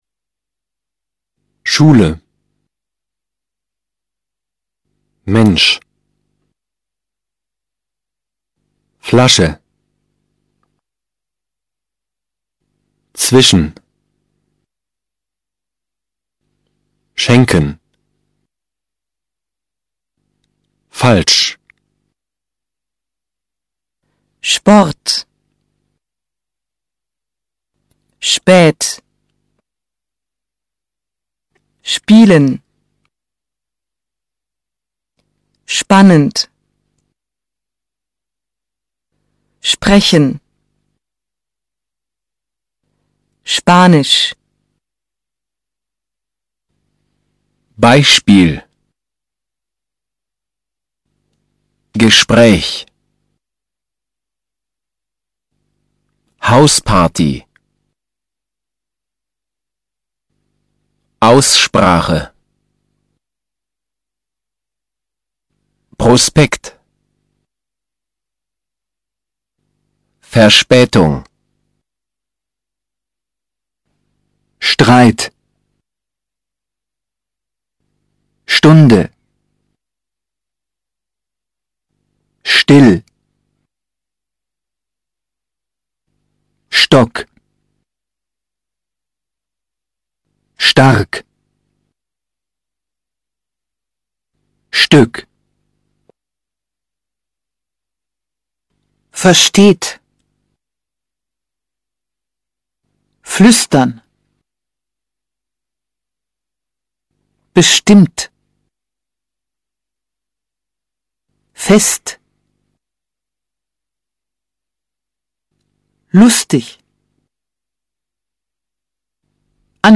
Here you will find practical exercises which will help you learn how to pronounce typical German sounds. Repeat the words while tracing the graphic form.
SZZZZZZZZZZZZZZ
sch.mp3